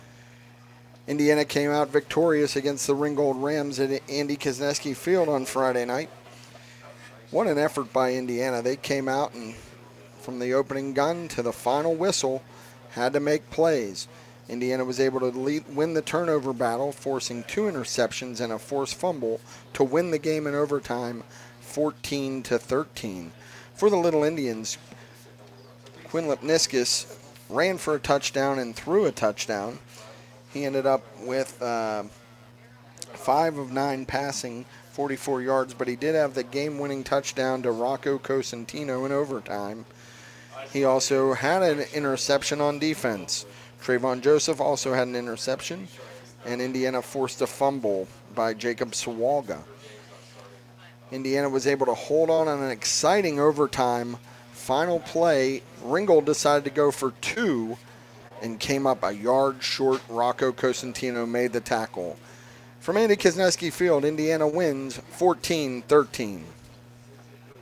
hsfb-indiana-vs-ringgold-recap.wav